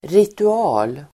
Uttal: [ritu'a:l]